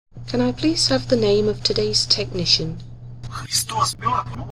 Sony B300 and reversed Russian background